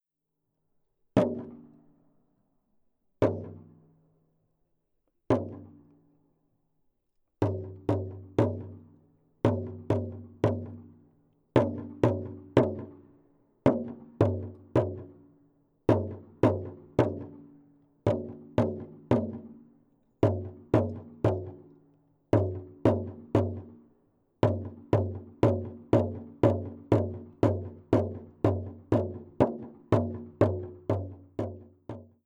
Rummutusta Valkealan Olhavanvuoren kalliomaalauksen edustalla, 29 metrin päässä kalliosta. Rummuniskut synnyttävät useita eri suunnista saapuvia kaikuja.